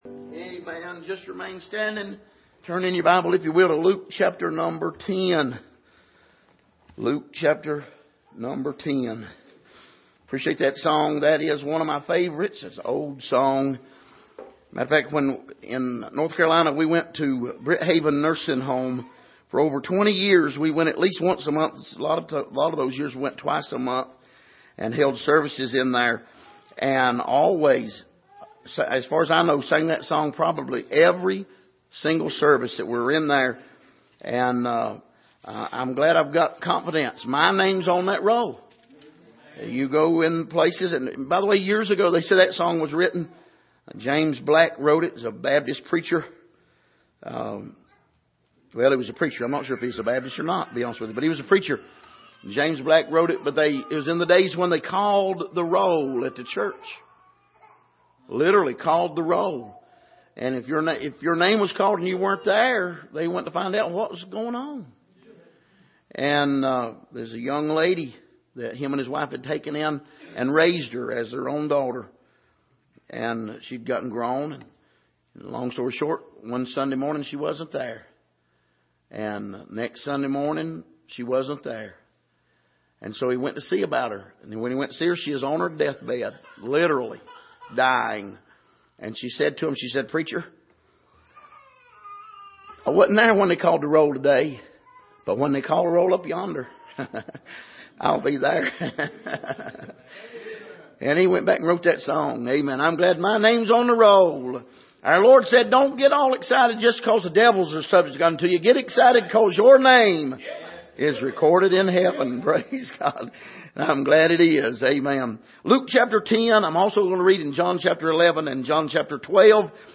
Passage: Luke 10:38-42 Service: Sunday Morning